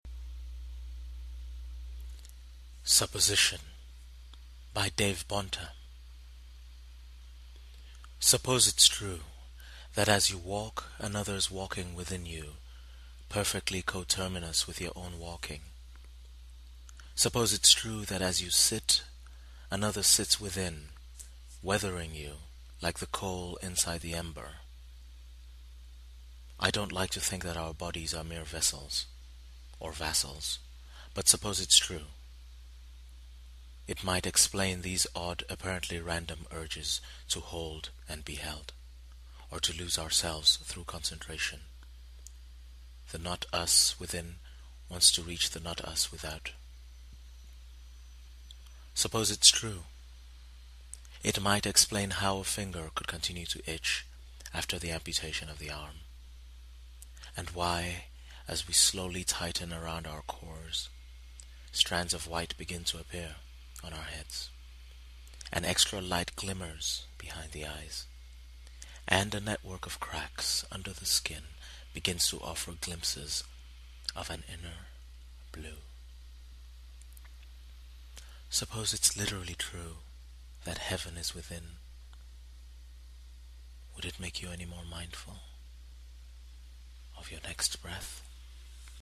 Today, I’m blown away by the reading of it – what a beautiful voice! what suppressed emotion!
I suppose the combination of the mellifluous voice and what’s provoked in the mind by the words themselves.
Me, I just like hearing my own words in a foreign accent: given the subject of the poem, that makes total sense.